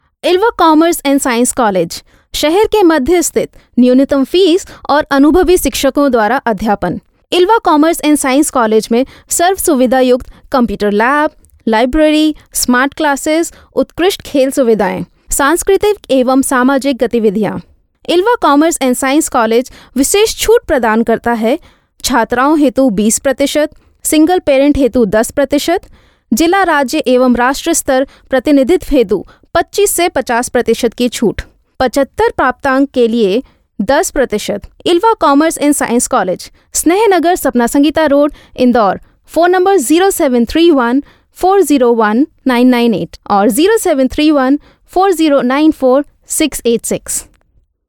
Native speaker Female 20-30 lat
Narracja
College-Film-Narration.mp3